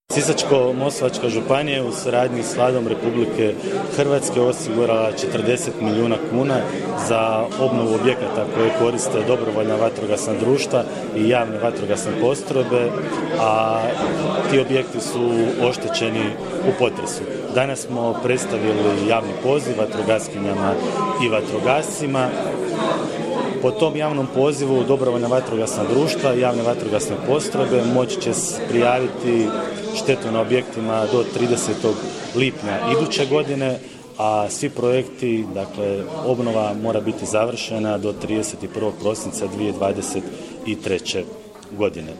U petak, 16. prosinca 2022. godine, u prostoru DVD-a Desna Martinska Ves predstavljen je Javni poziv za dodjelu bespovratnih sredstava za obnovu objekata koje koriste javne vatrogasne postrojbe i dobrovoljna vatrogasna društva na potresom pogođenom području.